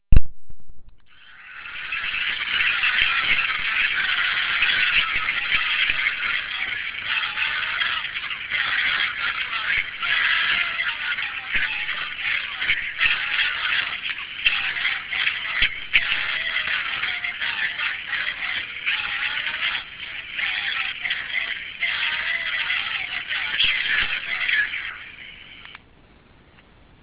Filmato Curva Sud # 1